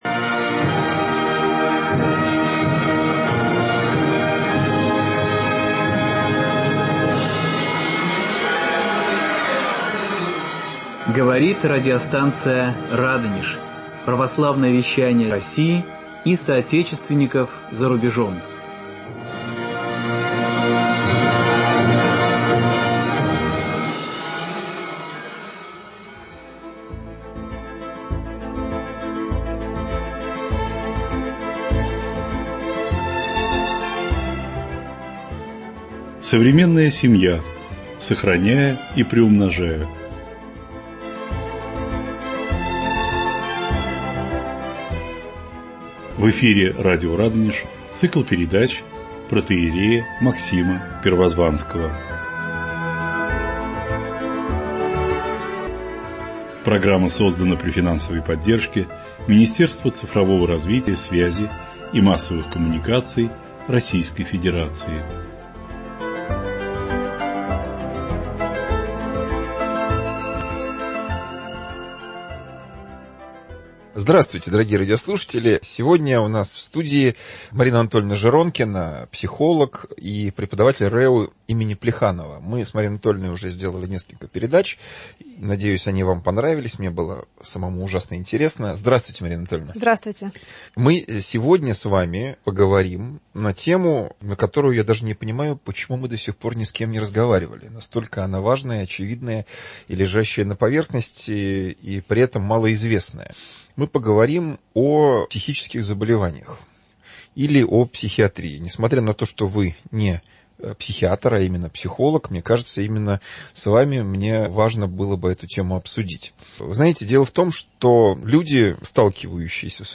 вместе с гостем, семейным психологом-консультантом